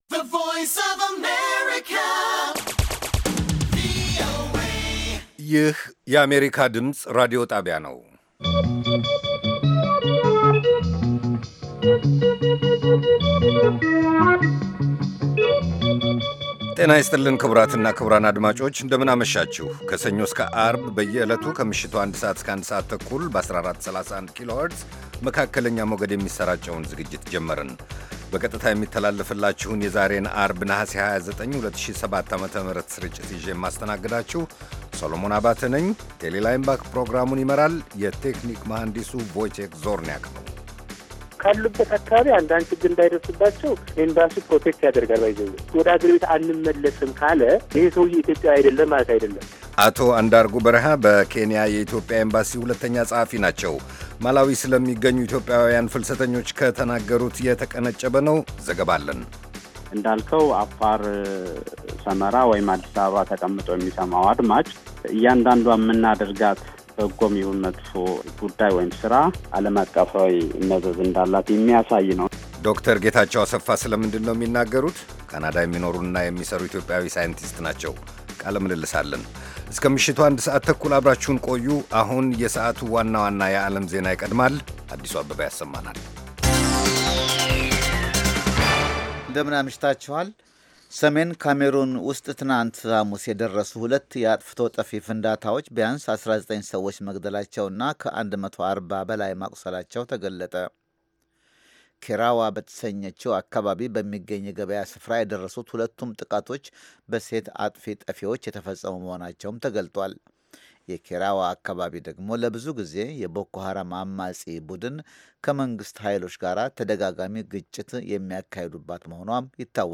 ከምሽቱ አንድ ሰዓት የአማርኛ ዜና